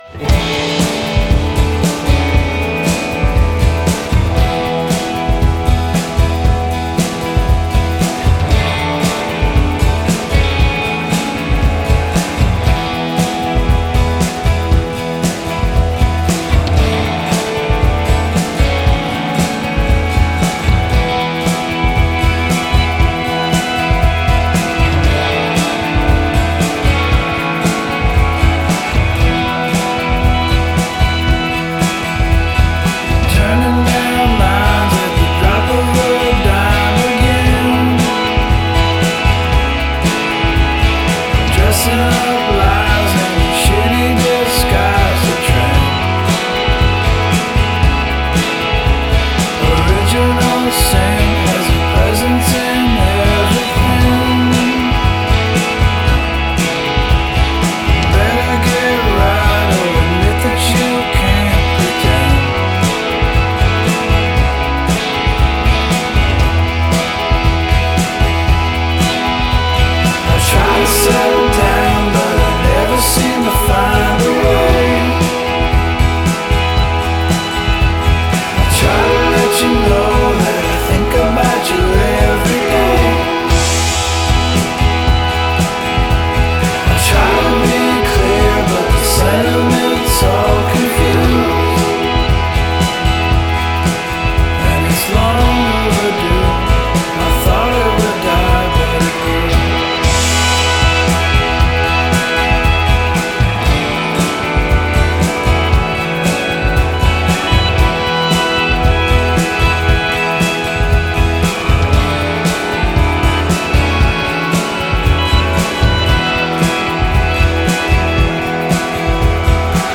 harmonica
switched between guitar, banjo, and keyboards.